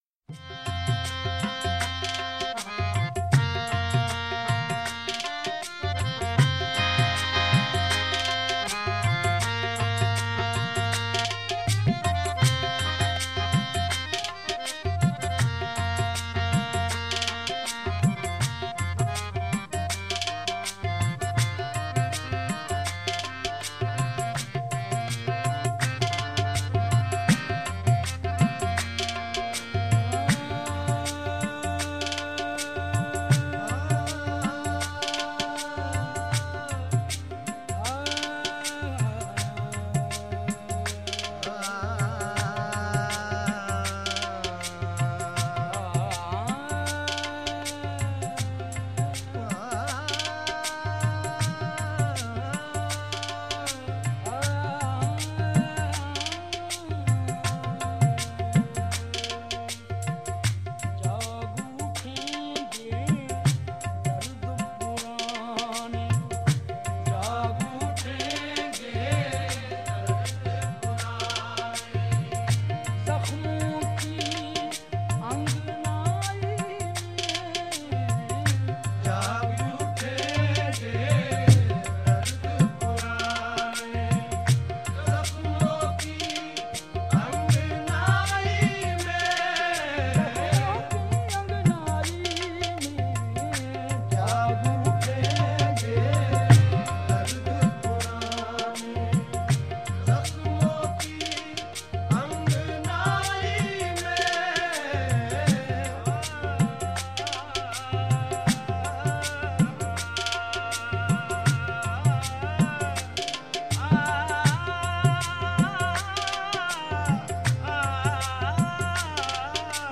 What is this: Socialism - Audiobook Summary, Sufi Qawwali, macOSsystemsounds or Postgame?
Sufi Qawwali